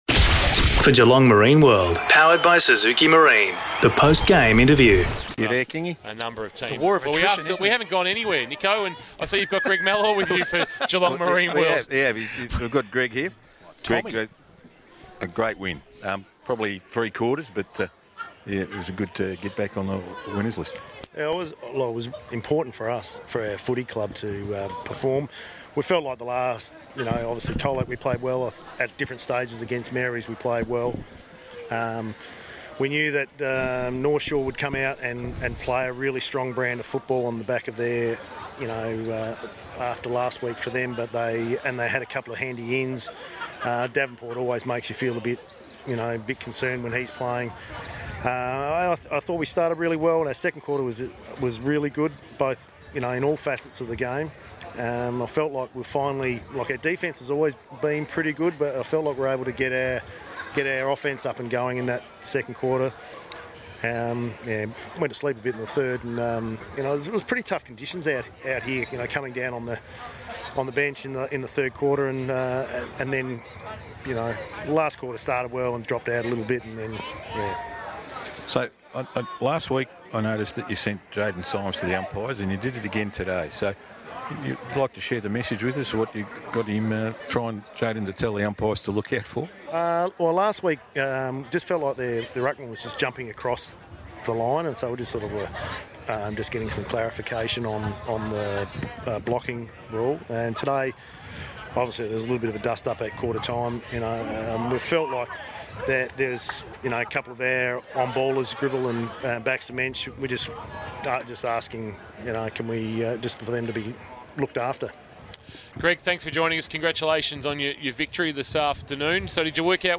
2022 – GFL ROUND 9 – GEELONG WEST vs. NORTH SHORE: Post-match Interview